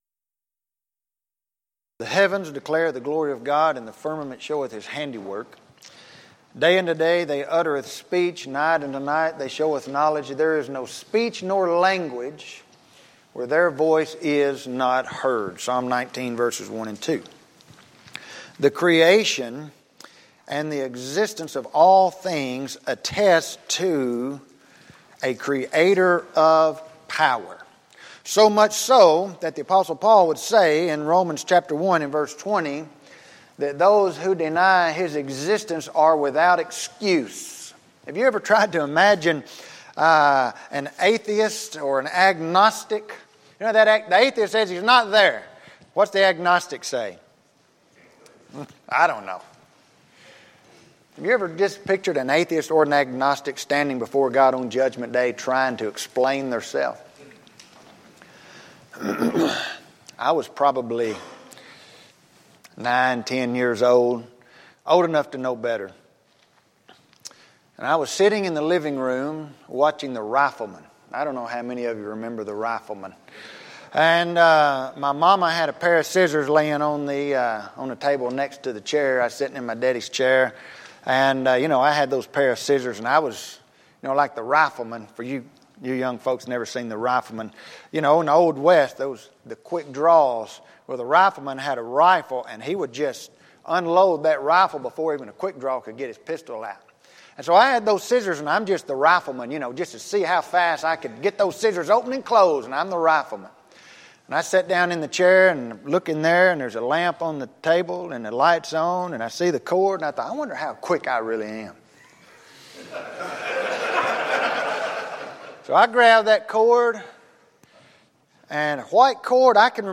Event: 5th Annual Men's Development Conference
lecture